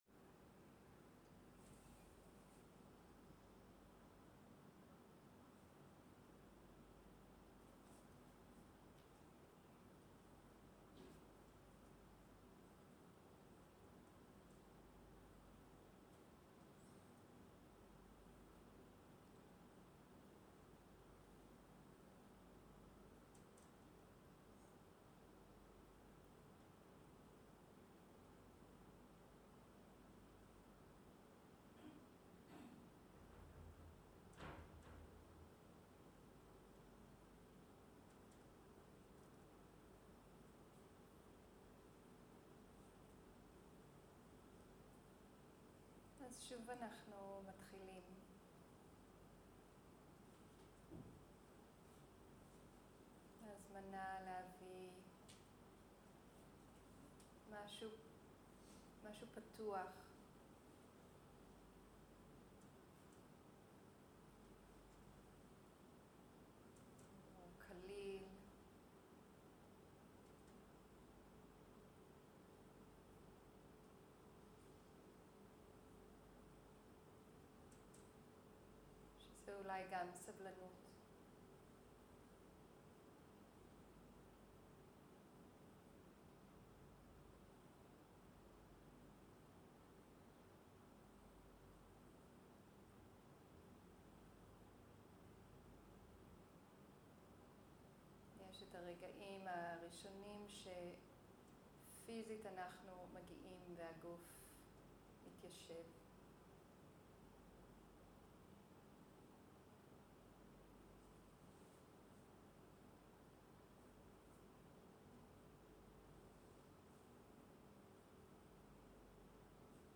הנחיות מדיטציה
שיחת הנחיות למדיטציה
איכות ההקלטה: איכות גבוהה